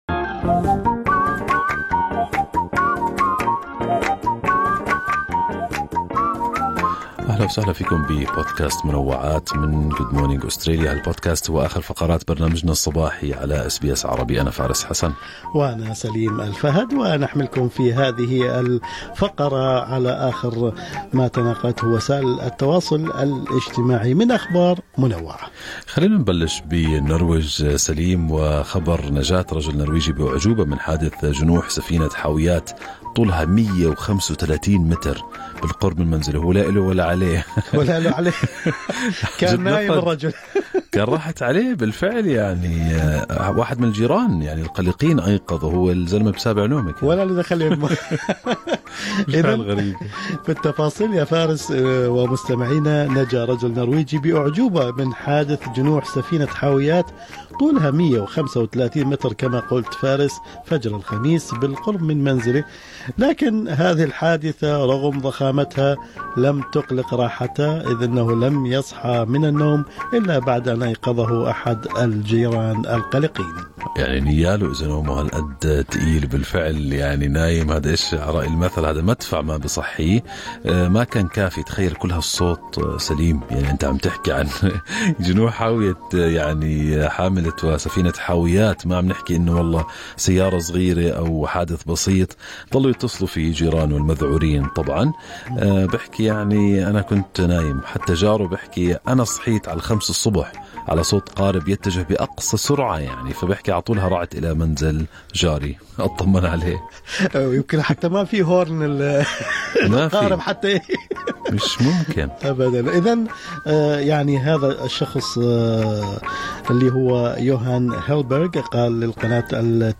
نقدم لكم فقرة المنوعات من برنامج Good Morning Australia التي تحمل إليكم بعض الأخبار والمواضيع الأكثر رواجاً على مواقع التواصل الاجتماعي.